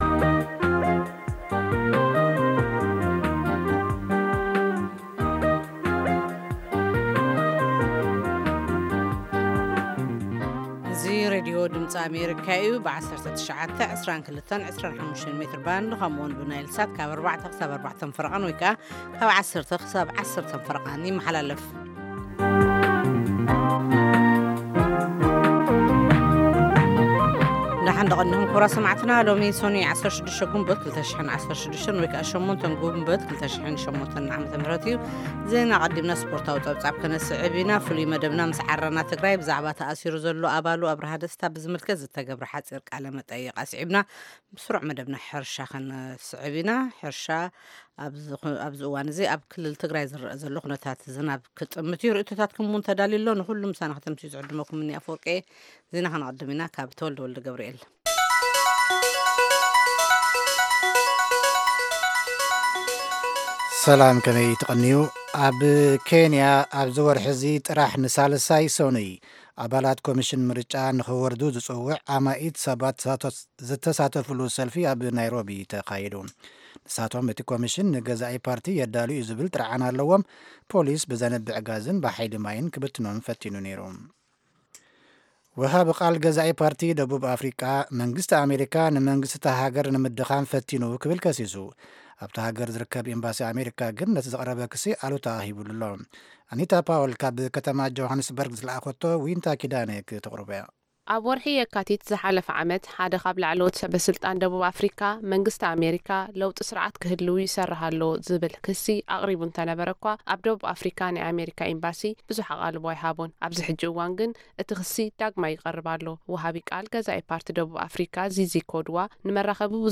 ፈነወ ትግርኛ ብናይ`ዚ መዓልቲ ዓበይቲ ዜና ይጅምር ። ካብ ኤርትራን ኢትዮጵያን ዝረኽቦም ቃለ-መጠይቓትን ሰሙናዊ መደባትን ድማ የስዕብ ። ሰሙናዊ መደባት ሰኑይ፡ ሳይንስን ተክኖሎጂን / ሕርሻ